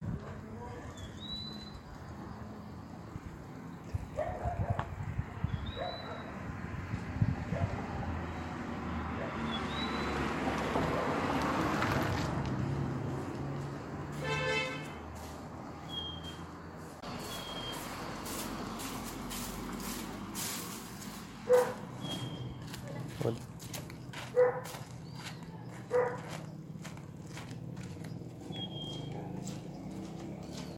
Tangará Común (Euphonia chlorotica)
Nombre en inglés: Purple-throated Euphonia
Localidad o área protegida: Chajarí
Condición: Silvestre
Certeza: Vocalización Grabada